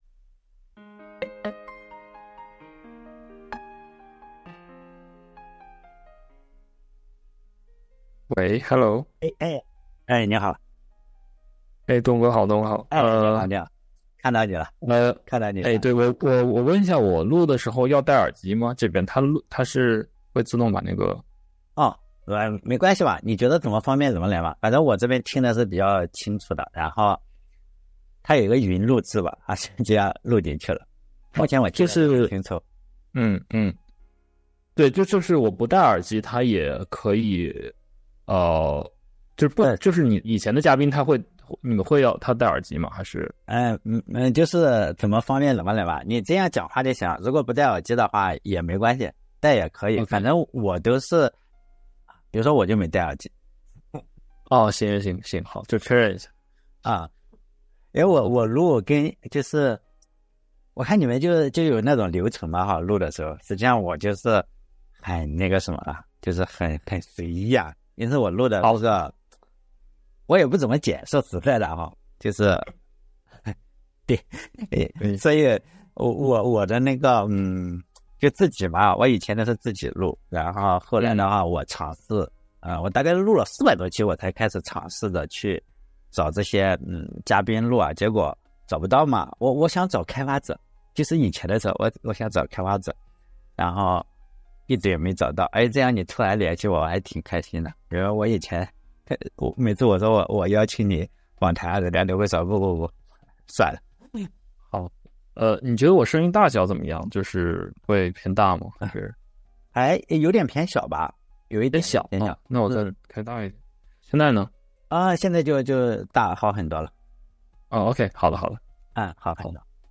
[音质调整版]